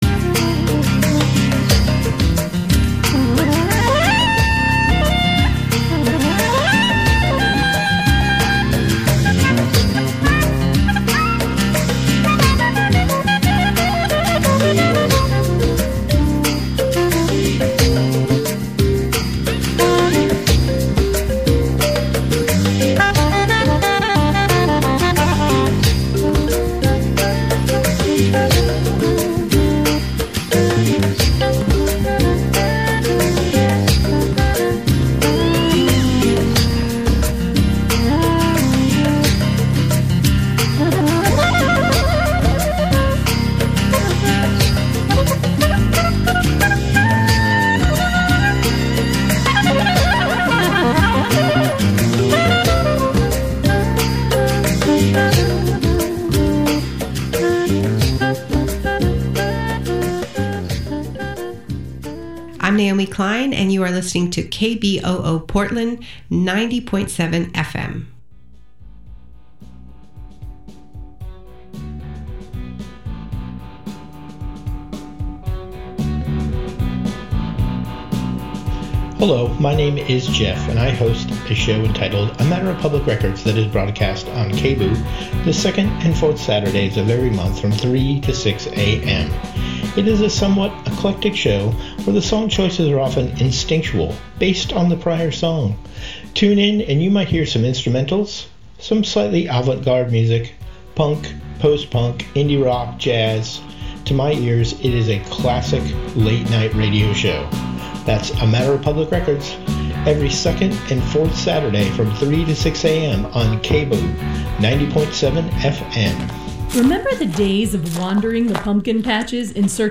Views, Reviews, and Interviews from a Socialist-Feminist, Anti-racist, Anti-colonial and LGBTQ-positive Perspective